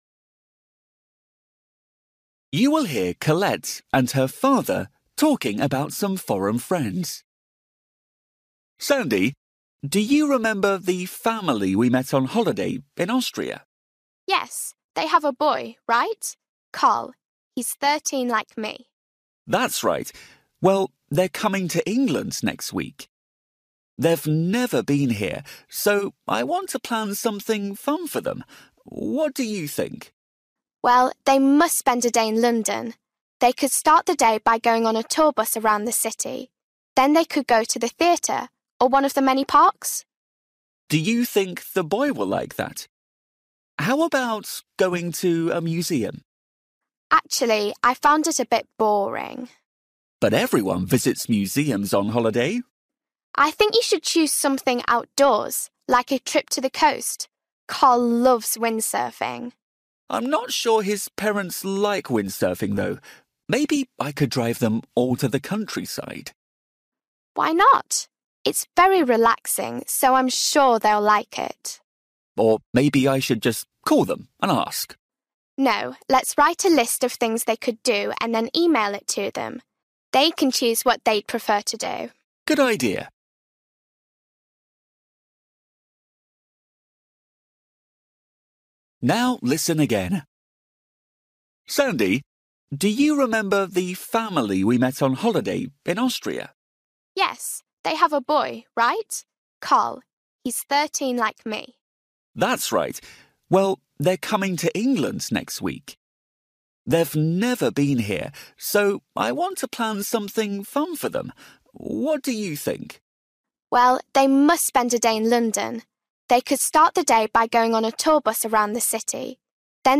Bài tập trắc nghiệm luyện nghe tiếng Anh trình độ sơ trung cấp – Nghe một cuộc trò chuyện dài phần 36